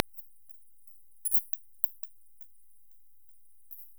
Stor brunfladdermus
Nyctalus noctula     Nnoc
Hanens sång inspelad i augusti 2017 nära Östra Sallerups kyrka: